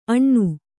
♪ aṇṇu